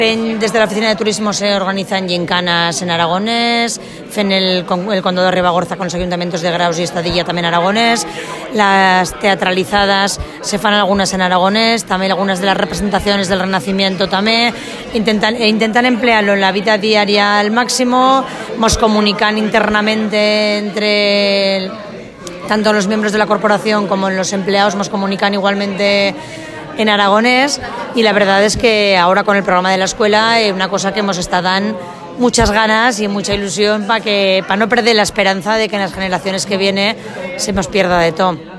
Audio de la concejala de Cultura del Ayuntamiento de Fonz, María Clusa: